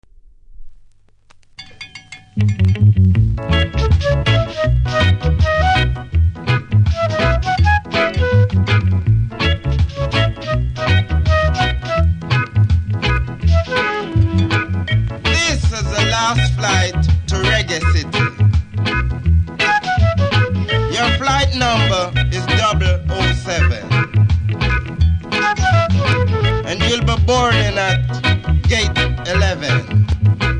FLUTE INST. & DJ CUT
多少うすキズありますが音は良好なので試聴で確認下さい。